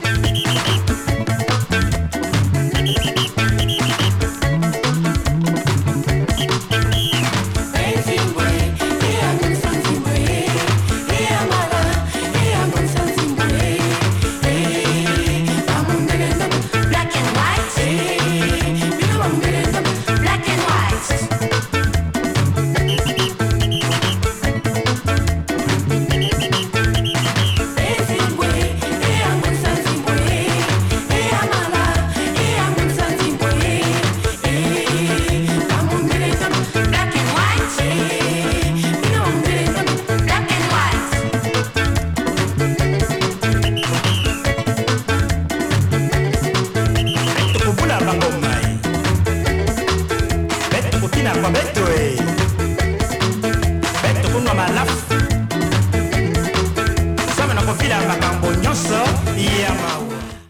打ち込みドラムを導入したモダンで都会的なアレンジの80'sスークース～アフロ・ポップを展開しています！